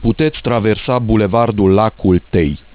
Comunicator vocal / Avertizare sonor� la treceri pietonale